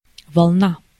Ääntäminen
IPA : /ˈweɪv/ US : IPA : [ˈweɪv]